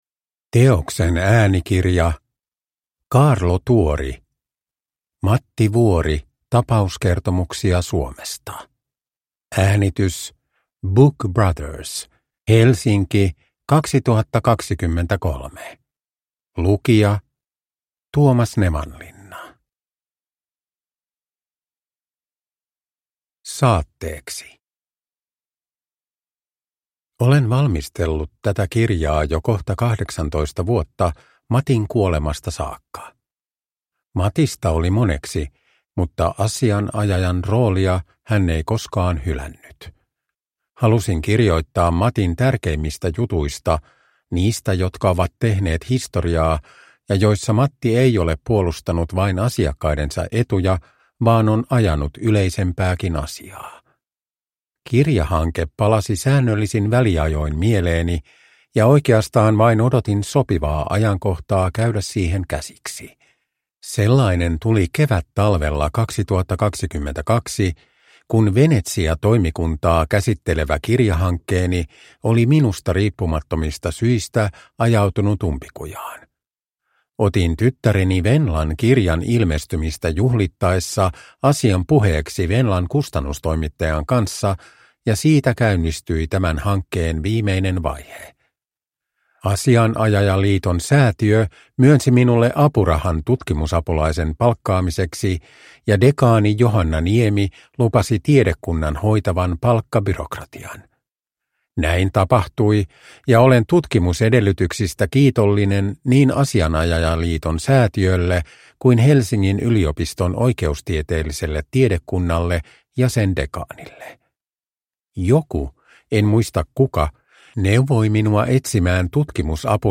Matti Wuori – Ljudbok – Laddas ner
Uppläsare: Tuomas Nevanlinna